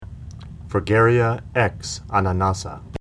Fragaria x ananassa (Pronounce) Strawberry Rosaceae Fruit Type: achenecetum (aggregate fruit of achenes) Note the enlarged receptacle, upon which the achenes (longitudinal-section at upper right) occur.